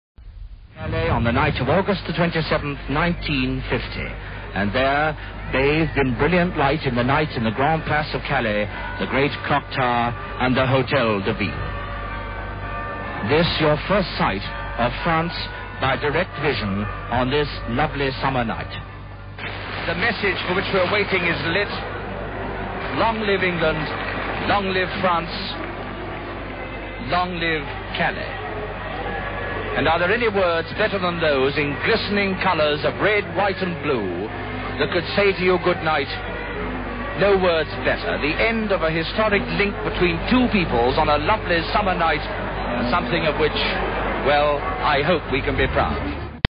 Extract from Richard Dimbleby's commentary during BBC outside broadcast from Calais.